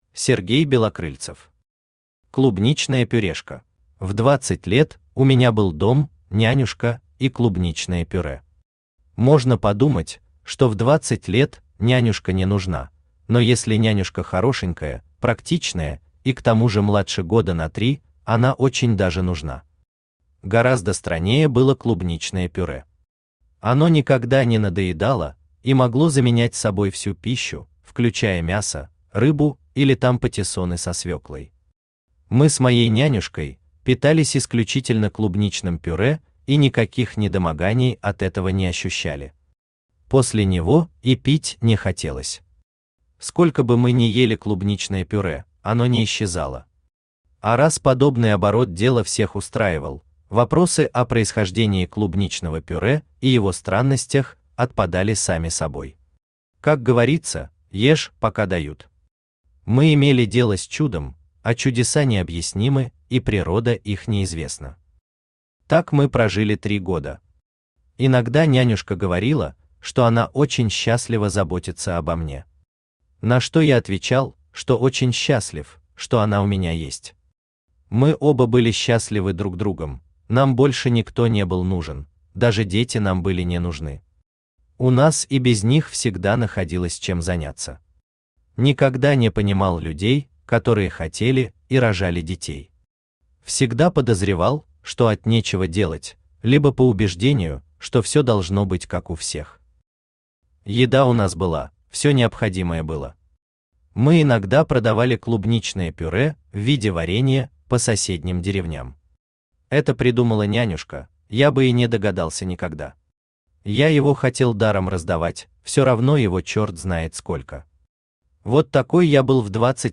Аудиокнига Клубничная пюрешка | Библиотека аудиокниг
Aудиокнига Клубничная пюрешка Автор Сергей Валерьевич Белокрыльцев Читает аудиокнигу Авточтец ЛитРес.